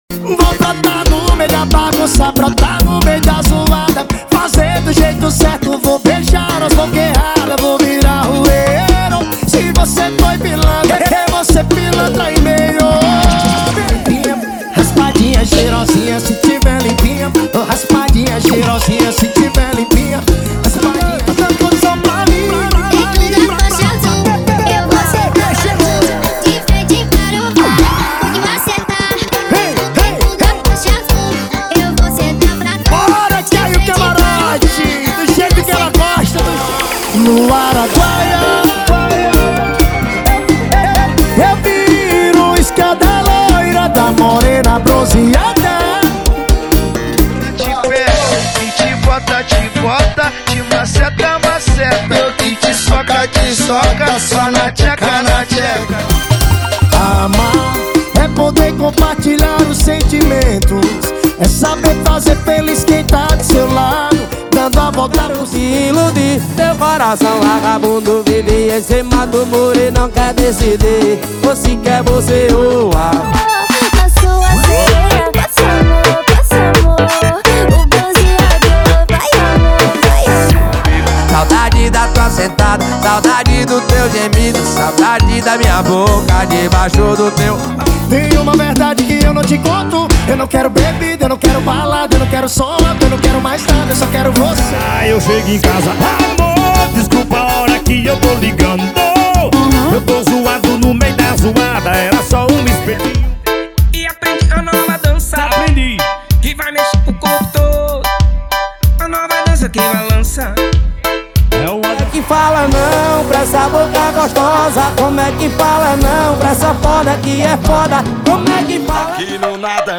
FORRÓ & PISEIRO = 55 Músicas
Sem Vinhetas
Em Alta Qualidade